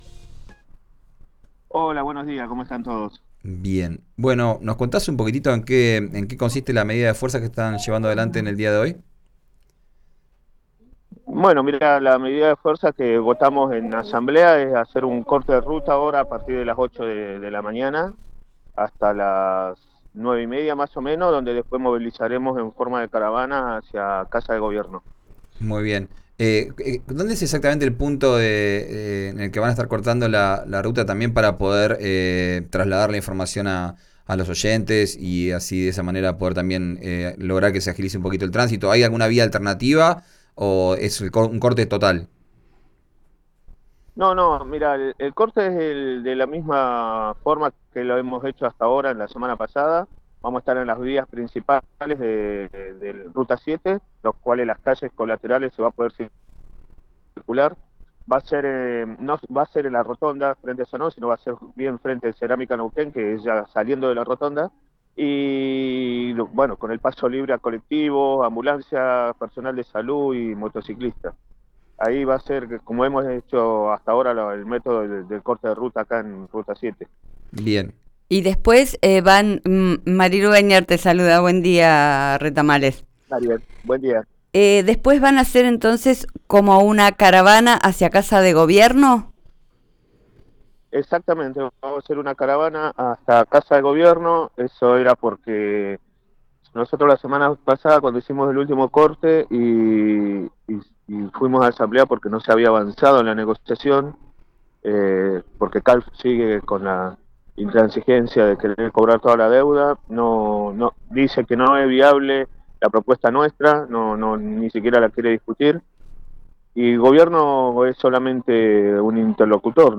en el aire de RÍO NEGRO RADIO: